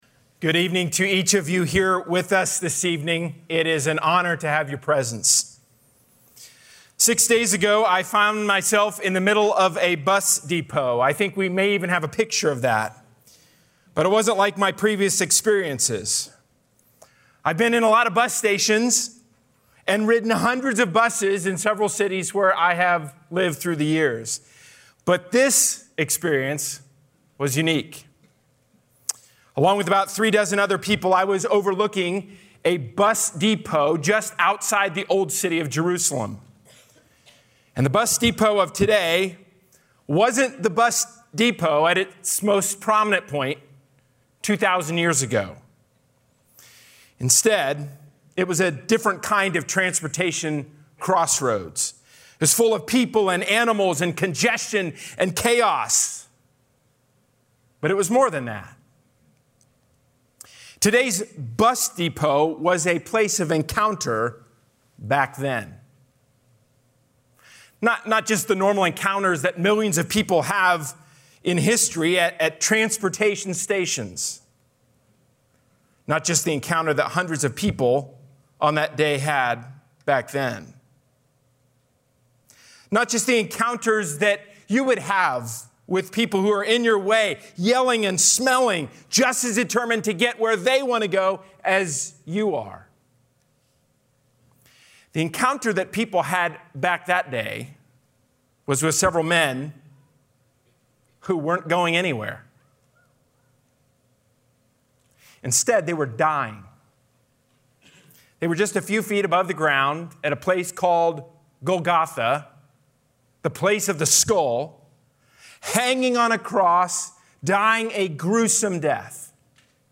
Sermonette from Good Friday, April 15, 2022